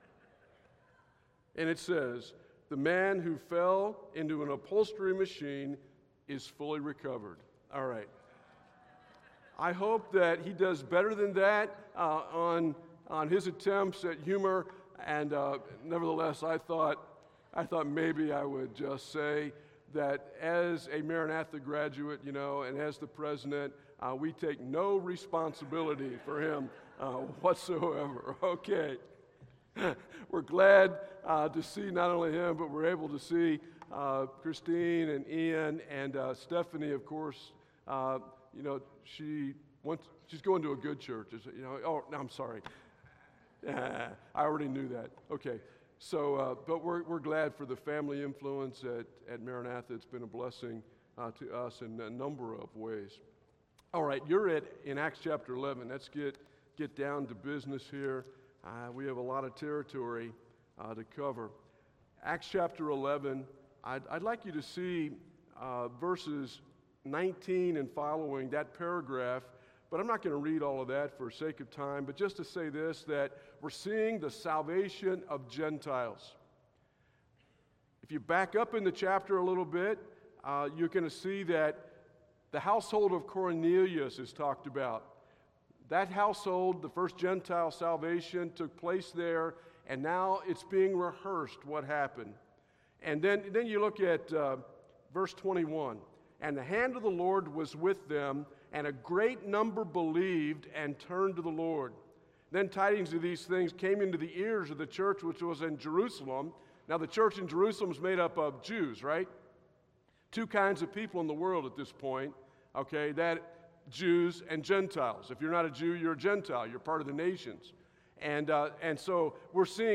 Acts 11:19-26 Service Type: Sunday Evening How can the Bible say Barnabas is a good man?